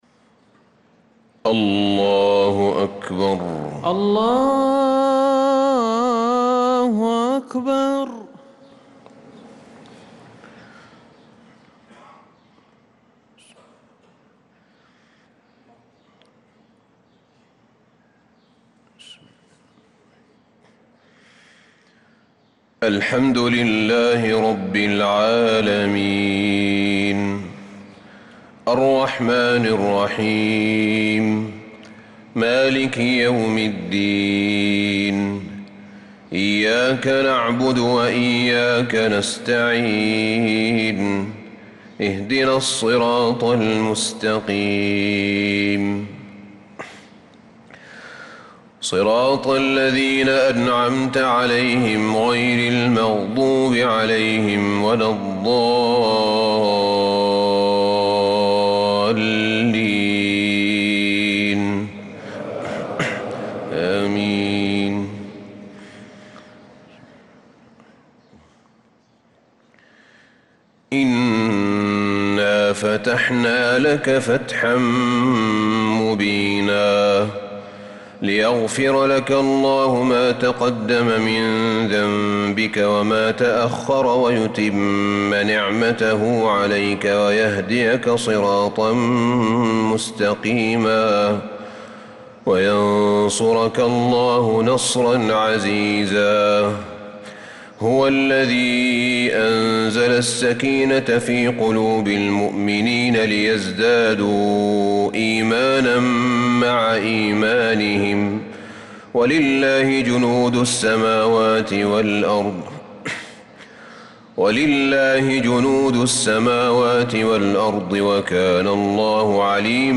صلاة الفجر للقارئ أحمد بن طالب حميد 30 ربيع الأول 1446 هـ
تِلَاوَات الْحَرَمَيْن .